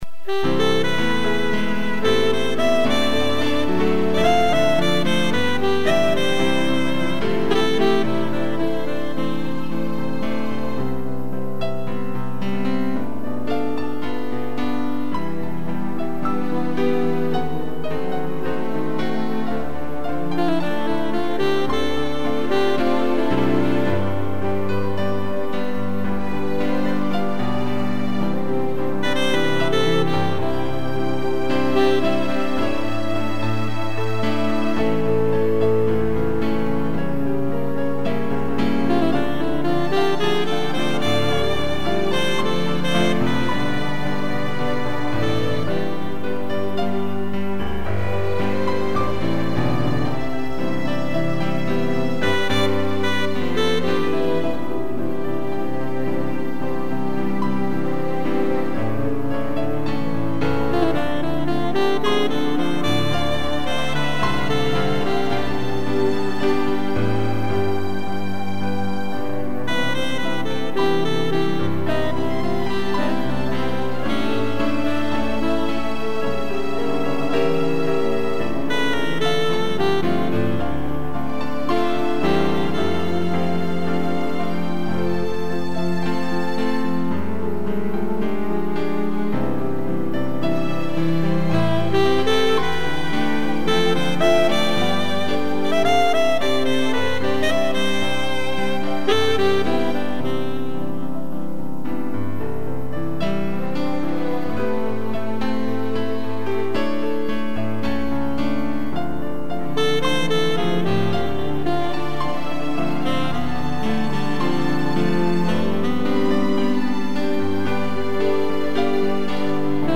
2 pianos, sax e strings
instrumental